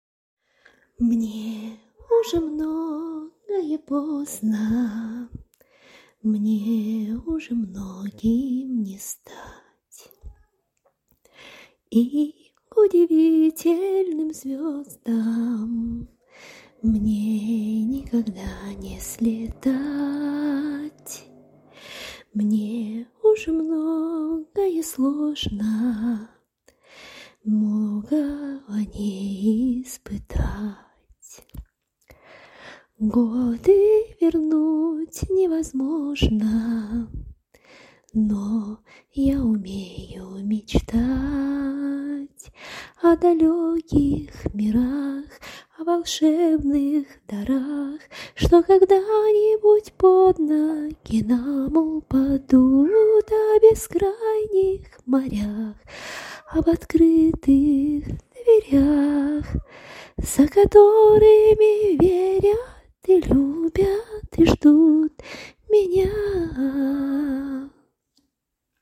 И правда на колыбельную похоже.